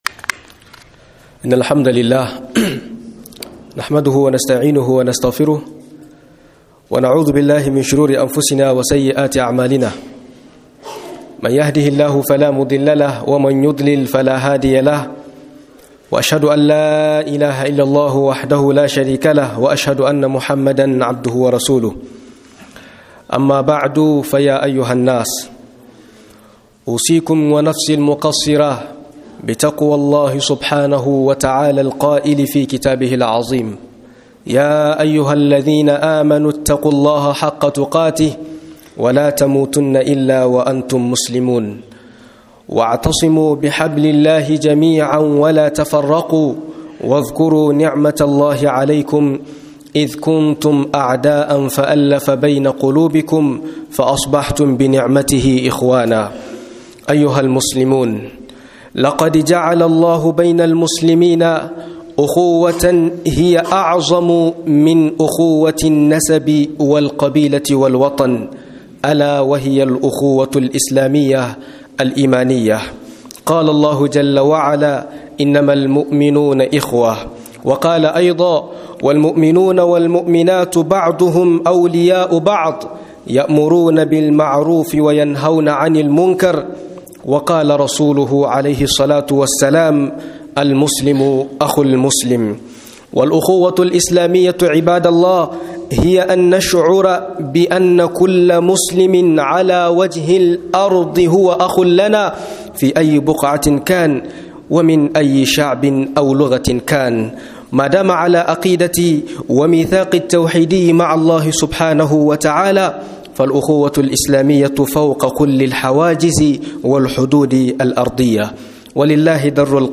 Mahimmancin yan uwataka ta Musulunci - MUHADARA